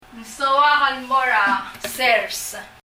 » farm 農地・農場 sers [sɛrs] 例） 「私は農場へ行きたい」 Ng soak el mo er a sers.